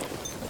tac_gear_3.ogg